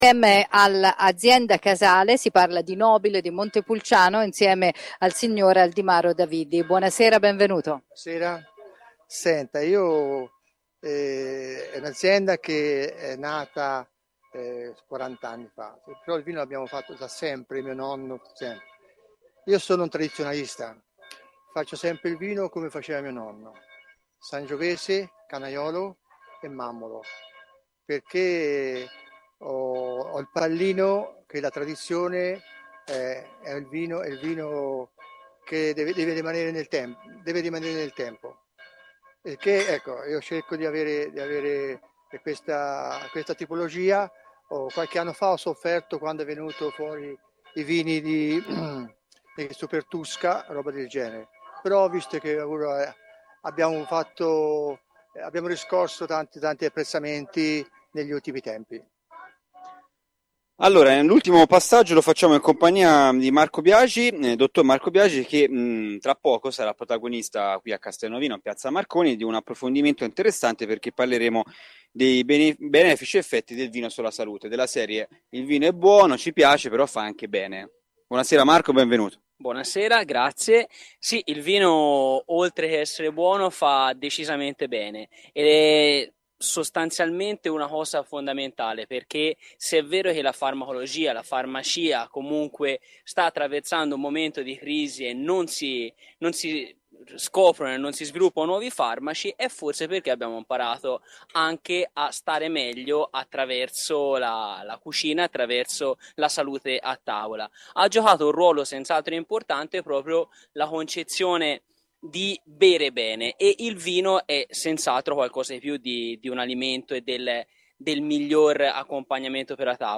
Wine station live da Castelnovino